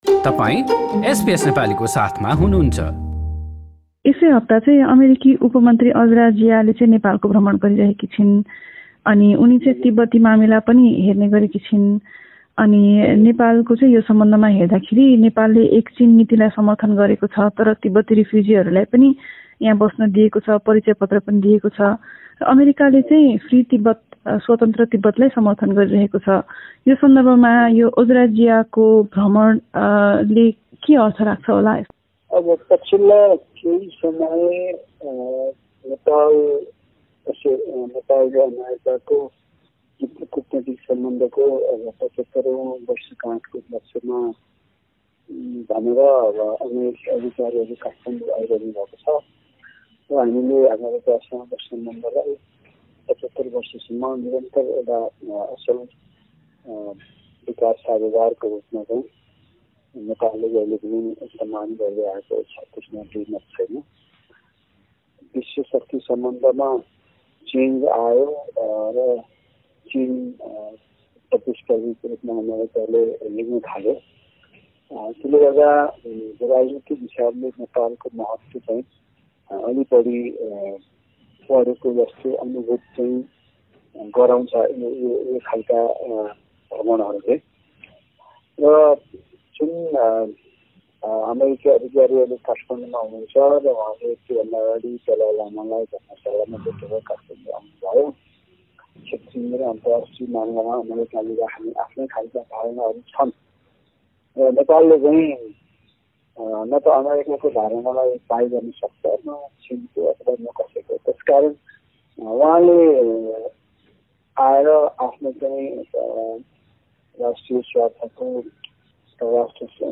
कुराकानी गरेका छौँ।